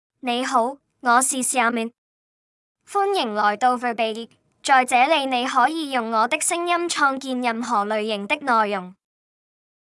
XiaoMin — Female Chinese AI voice
XiaoMin is a female AI voice for Chinese (Cantonese, Simplified).
Voice sample
Listen to XiaoMin's female Chinese voice.
Female